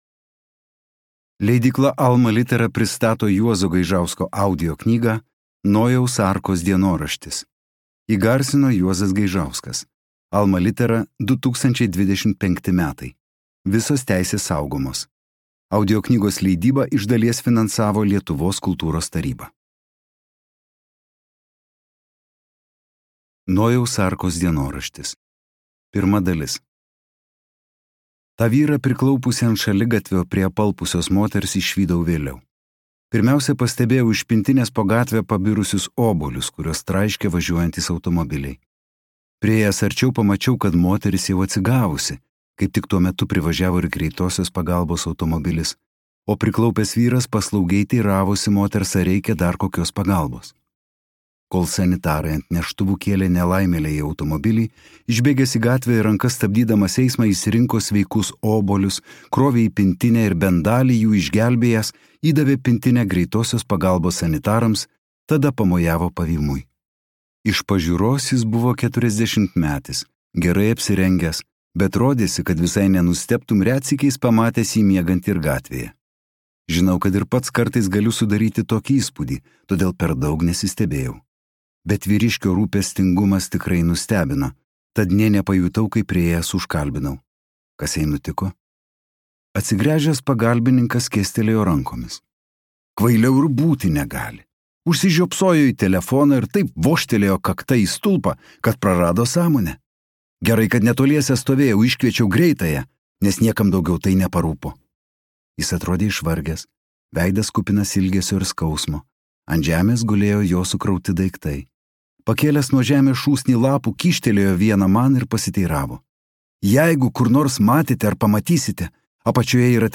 Nojaus Arkos dienoraštis | Audioknygos | baltos lankos